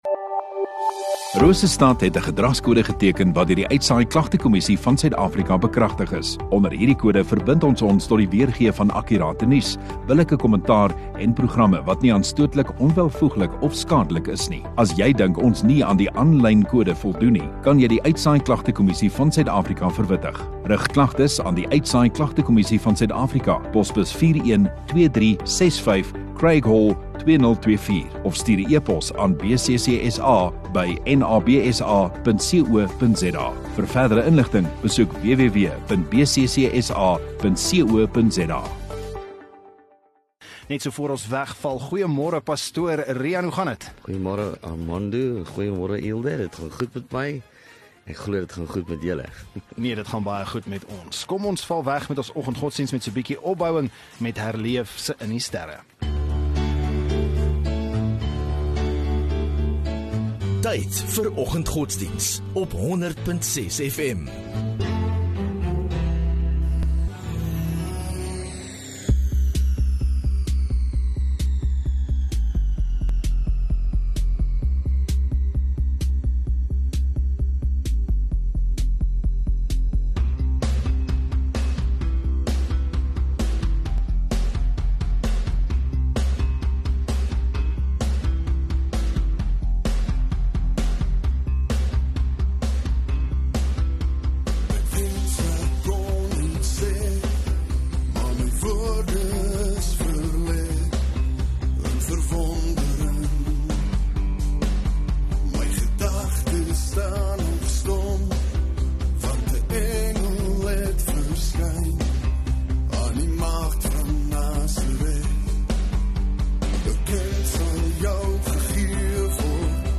View Promo Continue Radio Rosestad Install Rosestad Godsdiens 27 Nov Donderdag Oggenddiens